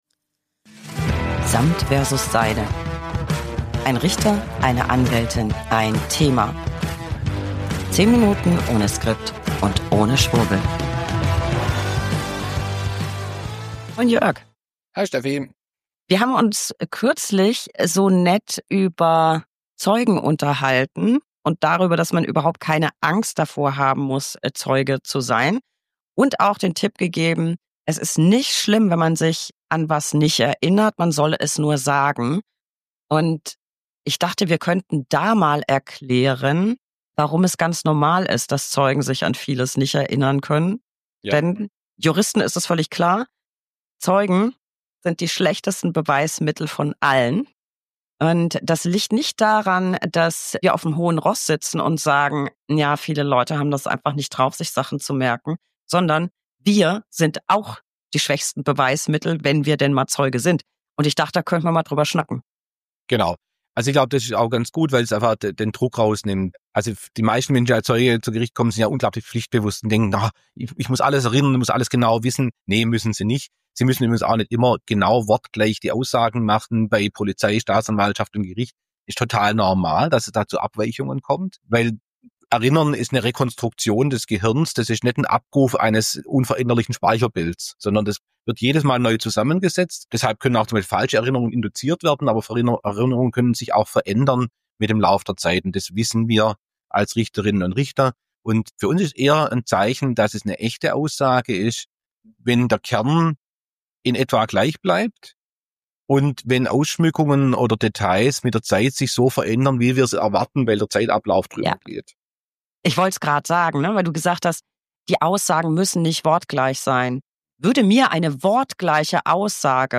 Beschreibung vor 1 Jahr 1 Anwältin + 1 Richter + 1 Thema. 10 Minuten ohne Skript und ohne Schwurbel.